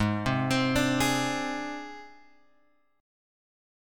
G# Major Flat 5th